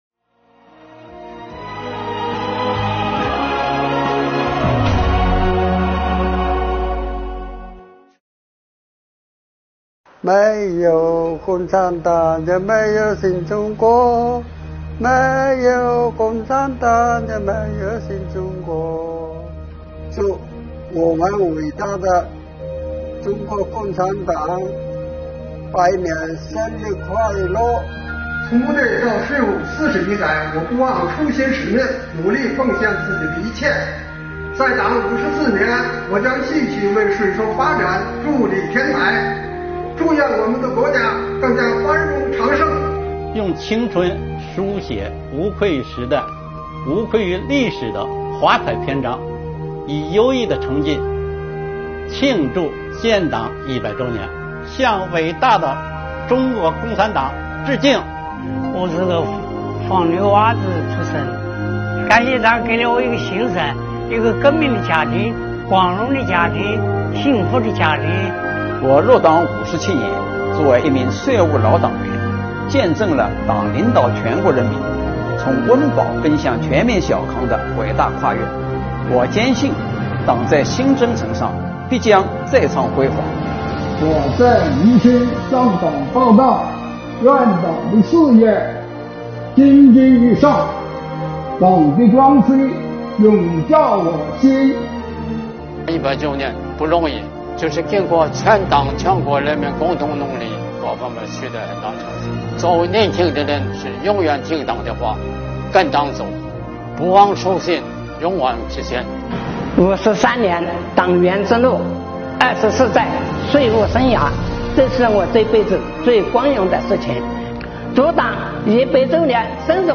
今天是建党100周年纪念日，税务系统广大党员干部面对镜头，为党送上诚挚的祝福。
作为老党员